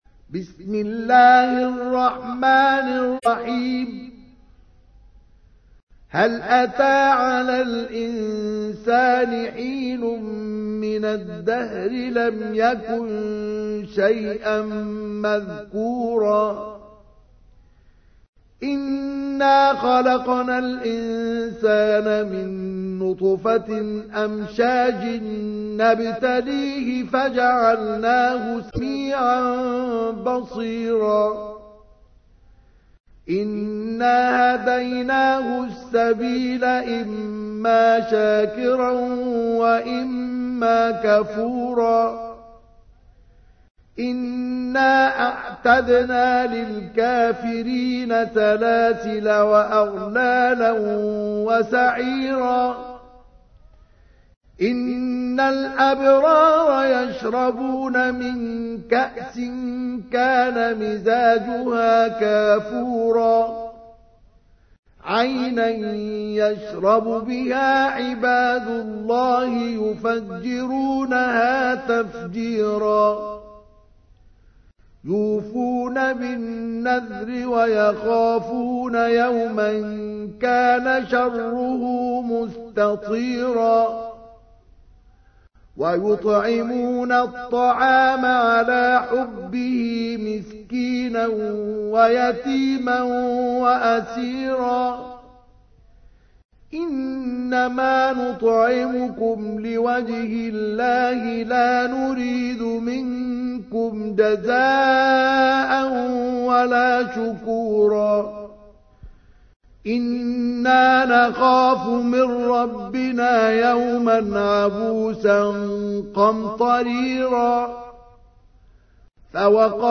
تحميل : 76. سورة الإنسان / القارئ مصطفى اسماعيل / القرآن الكريم / موقع يا حسين